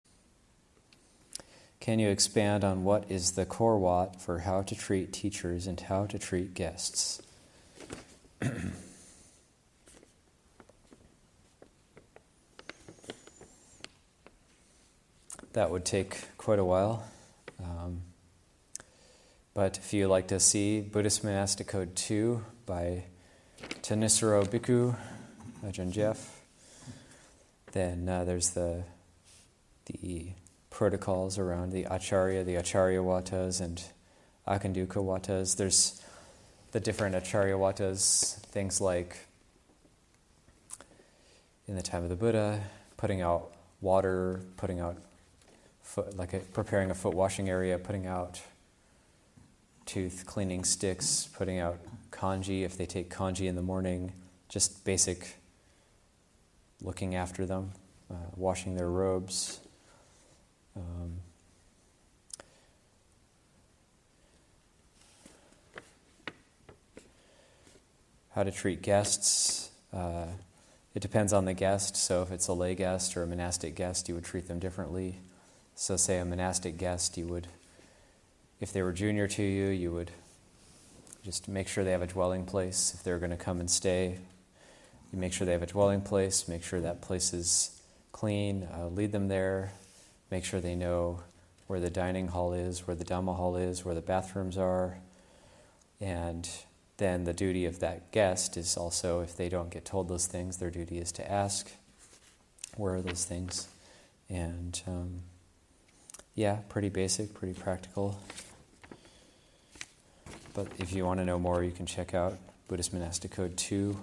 Abhayagiri 25th Anniversary Retreat, Session 16 – Jun. 13, 2021